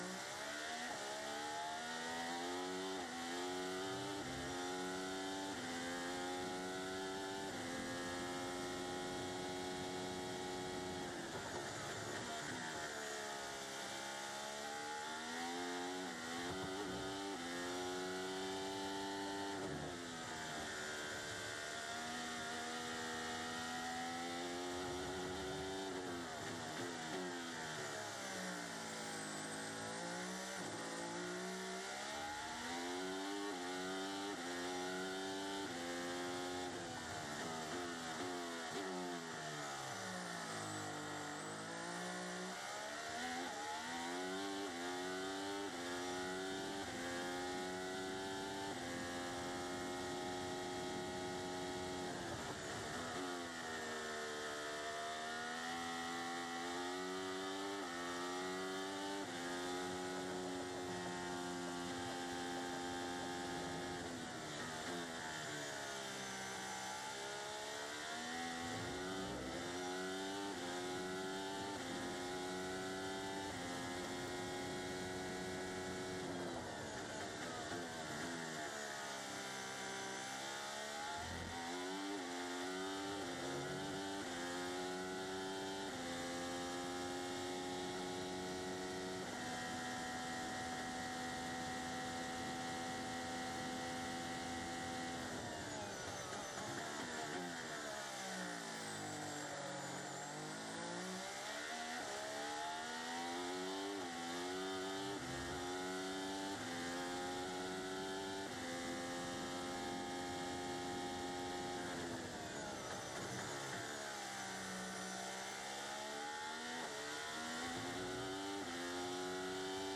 Team Radio (1)